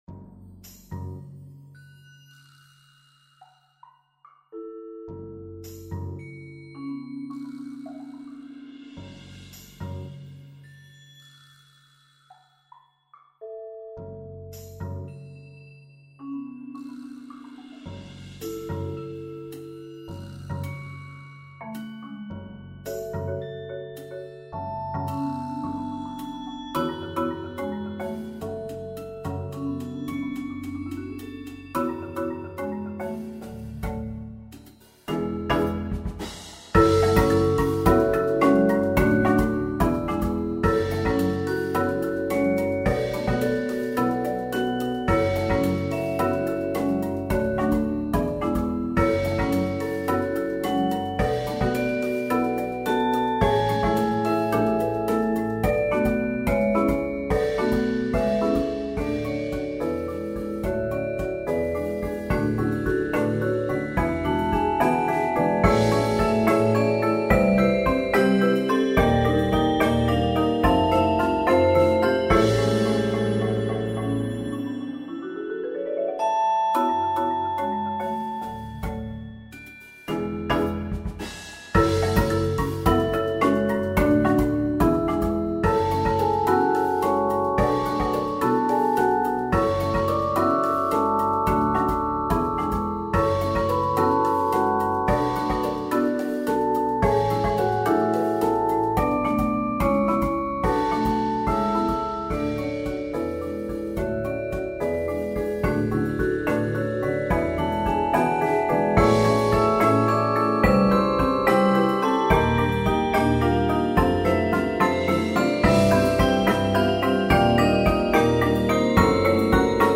Genre: Percussion Ensemble
# of Players: 10-11
Glockenspiel + High Tom
Vibraphone 1 + High Tom
Marimba 1 [4.3-octave] + Suspended Cymbal, Medium High Tom
Timpani [5 drums]
Drum Set
Bass Guitar [optional]